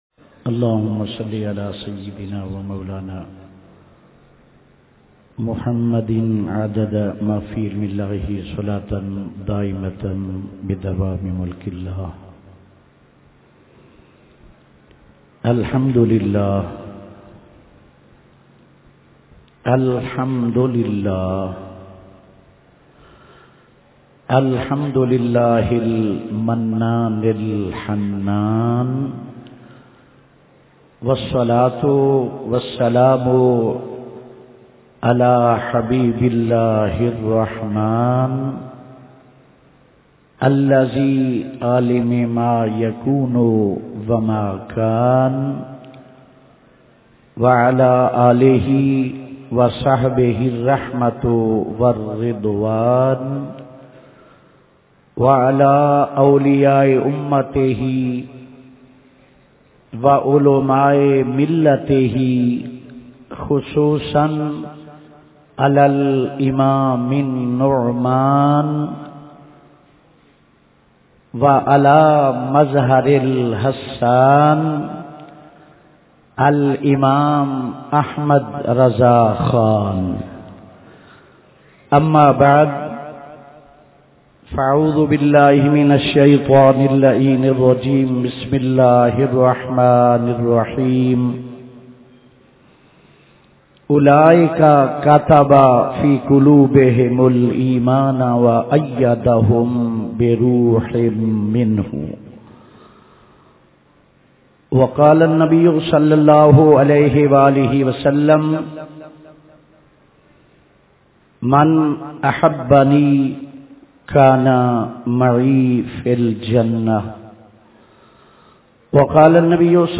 بیانات